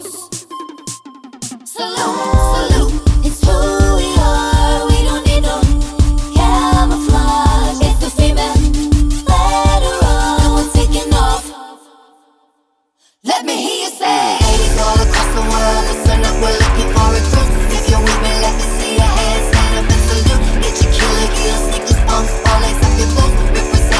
No Rap Version Pop (2010s) 4:08 Buy £1.50